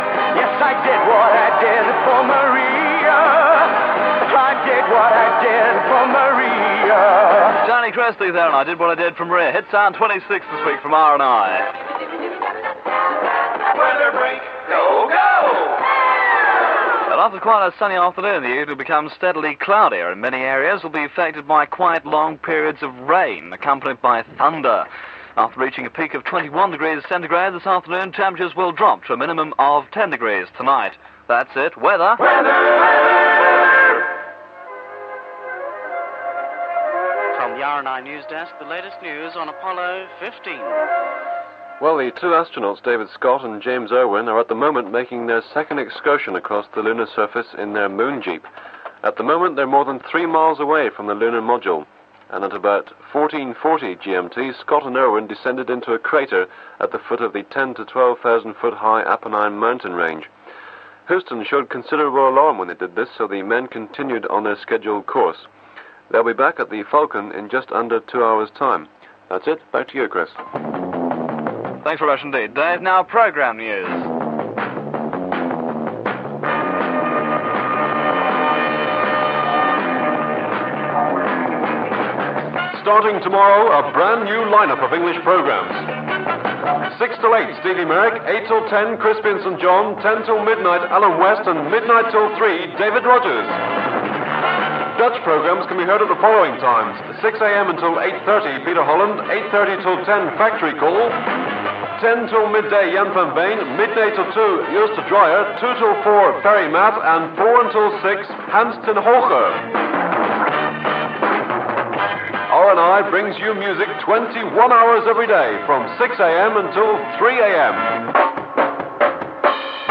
An extract from the Radio Northsea International ‘Super Hit 50’ show from 1st August 1971